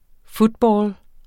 Udtale [ ˈfudˌbɒːl ]